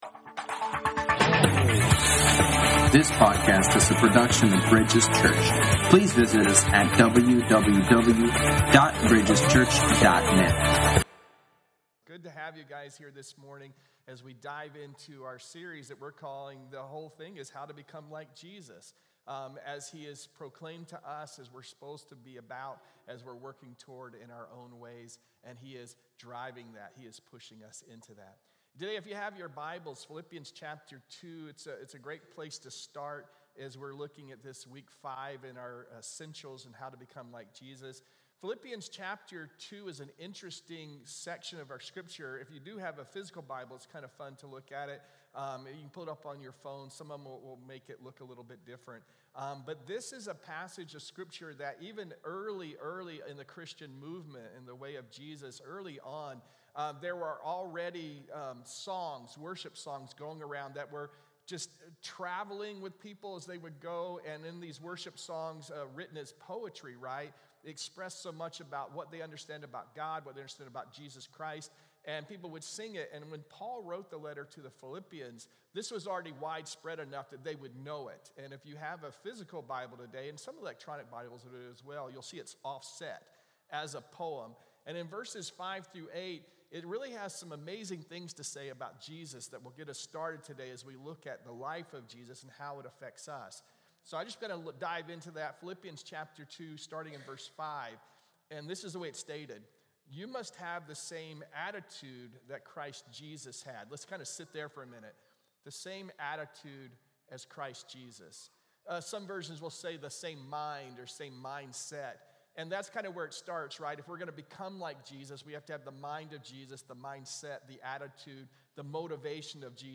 Sermons | Bridges Church